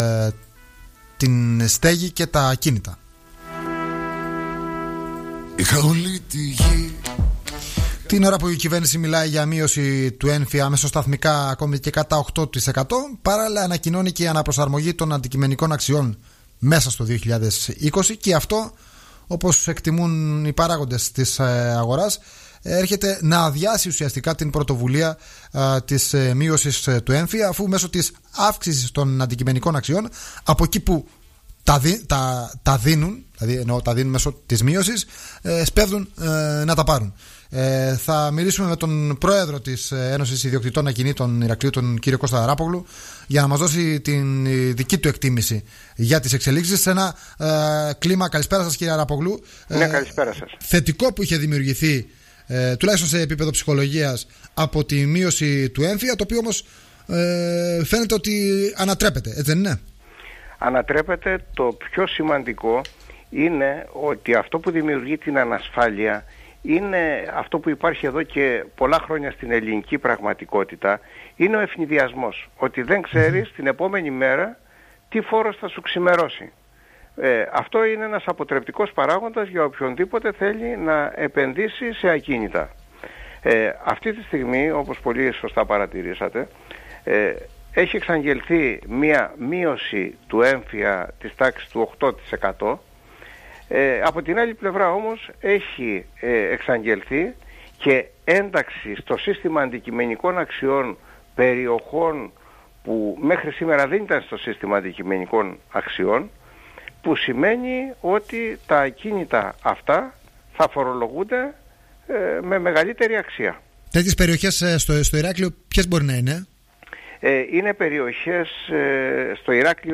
μίλησε στον Politica 89.8